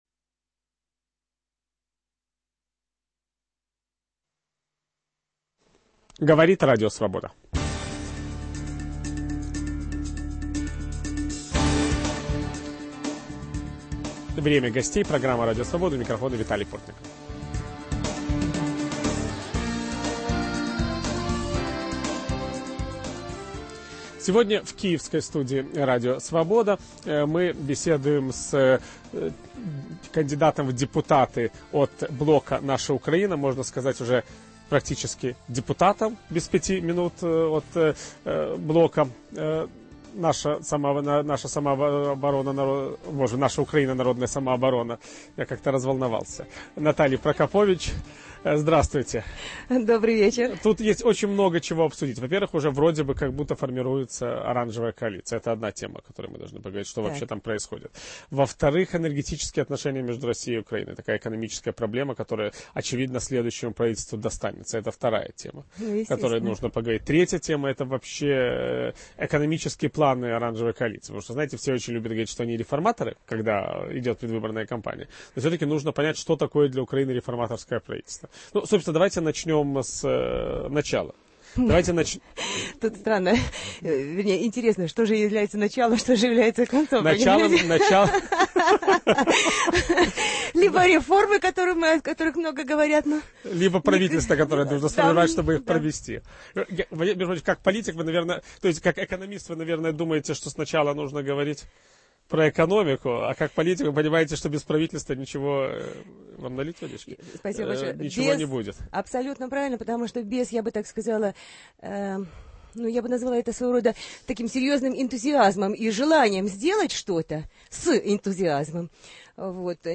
Каковы перспективы "оранжевой" коалиции? Ведущий программы Виталий Портников беседует с кандидатом в депутаты Верховной Рады